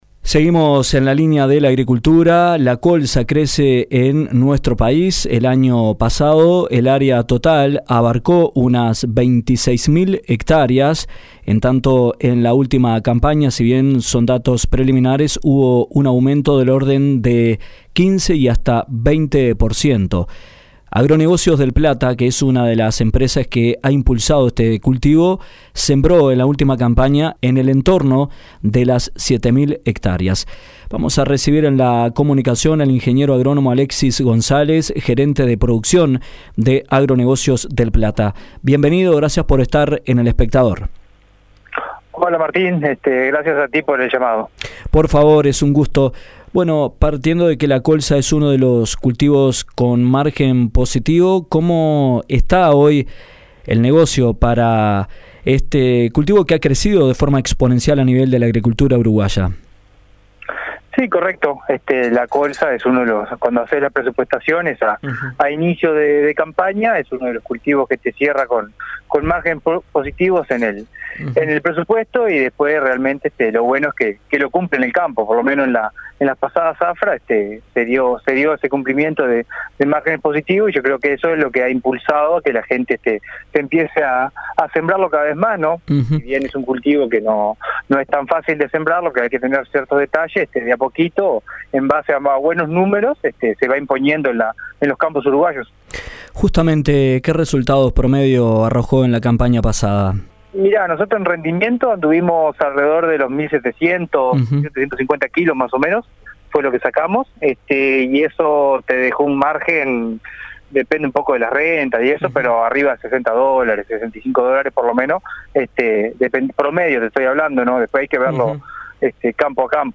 el entrevistado